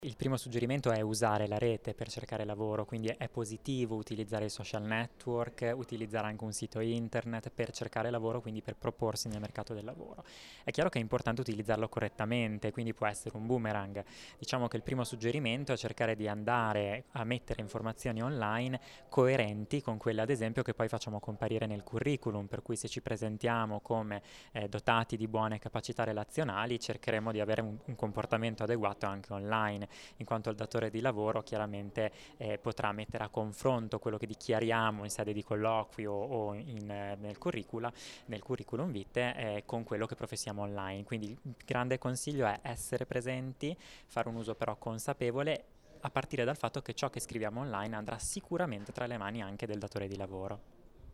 Oggi il convegno, presso il dipartimento della Conoscenza, a Trento